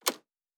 pgs/Assets/Audio/Sci-Fi Sounds/Mechanical/Device Toggle 20.wav at 7452e70b8c5ad2f7daae623e1a952eb18c9caab4
Device Toggle 20.wav